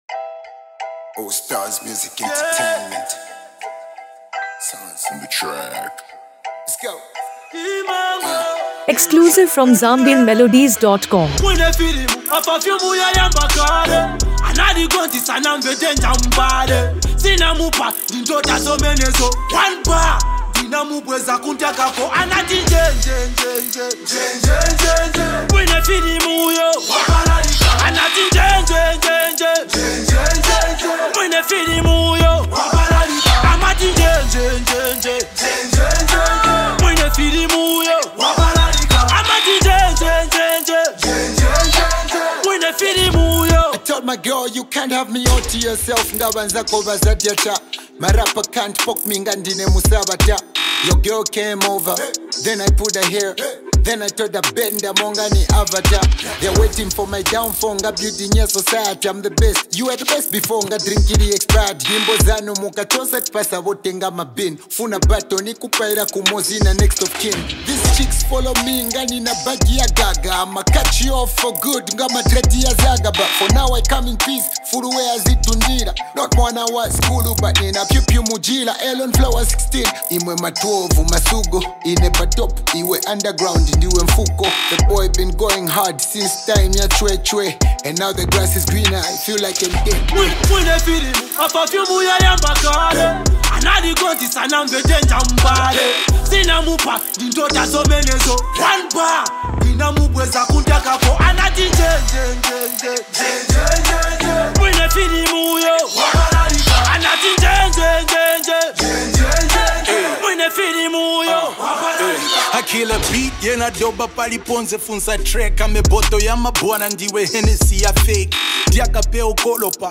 Zambian Music
Zambian hip-hop